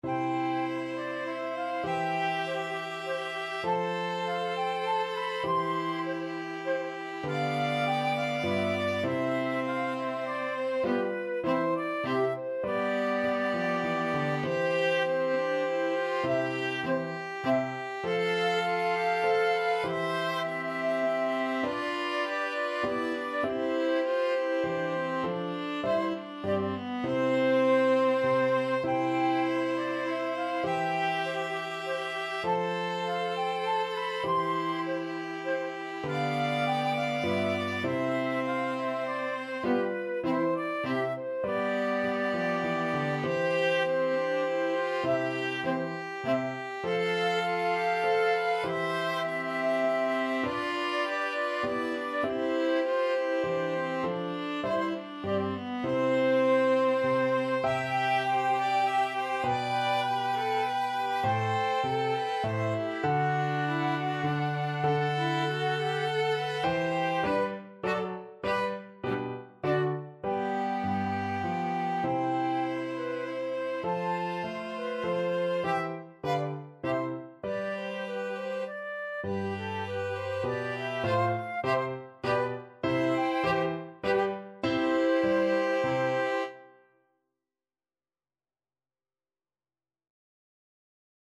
Free Sheet music for Flexible Ensemble and Piano - 3 Players and Piano
Flute
ViolinCello
Piano
3/4 (View more 3/4 Music)
C major (Sounding Pitch) (View more C major Music for Flexible Ensemble and Piano - 3 Players and Piano )
Classical (View more Classical Flexible Ensemble and Piano - 3 Players and Piano Music)